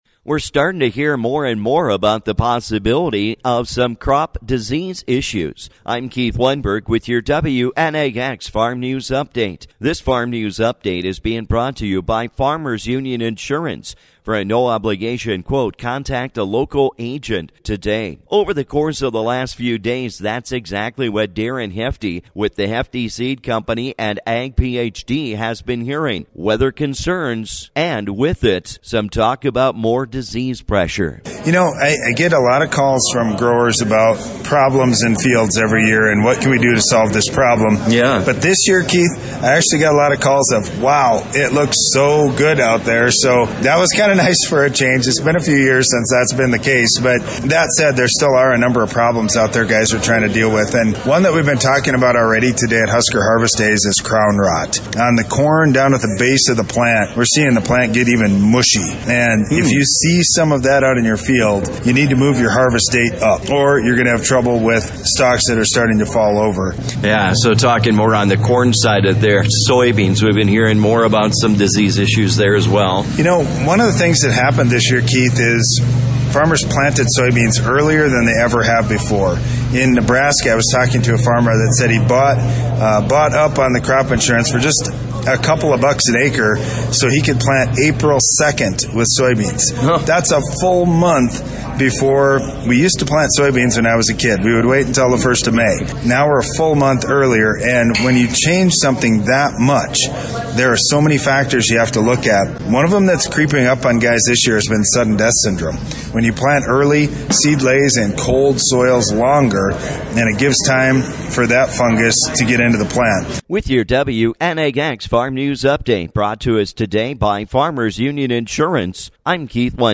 While attending Husker Harvest Days in Grand Island, Nebraska this week.